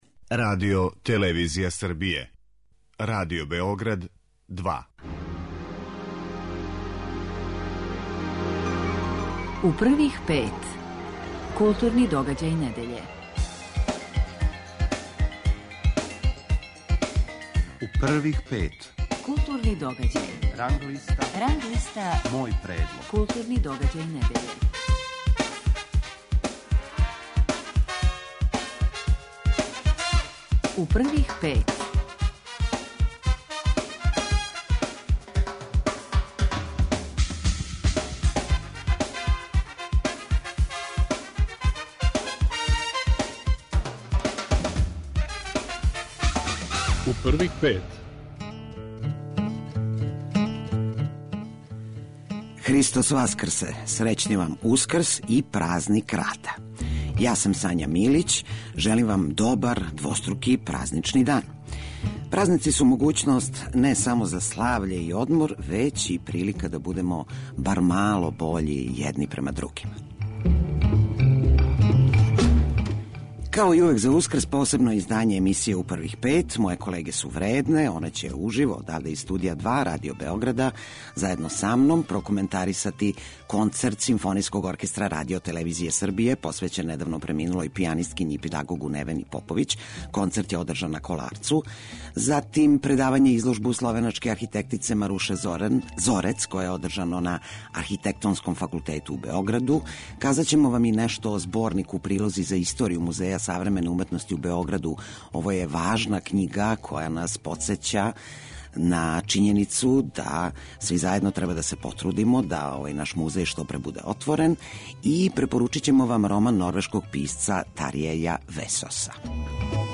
Књига 'Три поеме' академика Матије Бећковића биће тема разговора са овим песником у празничном издању емисије. Реч је о филозофско-религиозним стиховима који нам откривају помало неочекиваног, другачијег Бећковића.